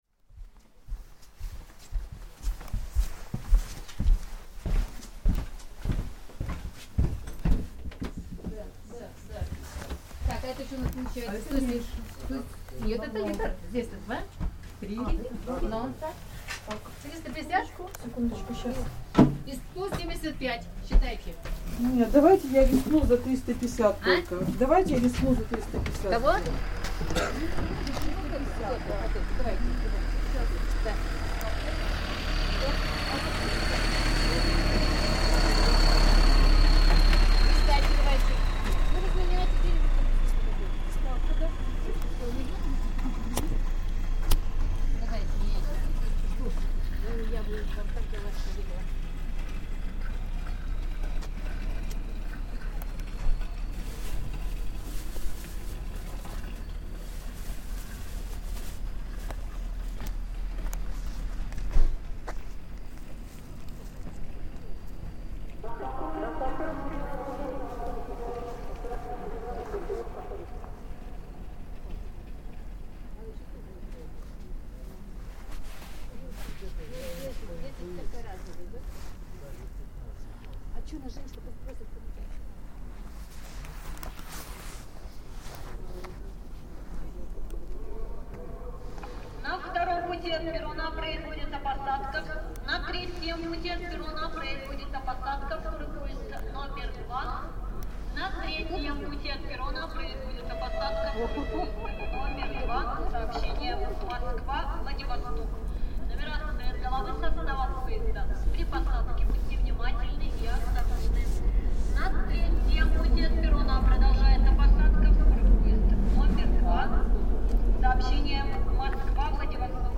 Zima train station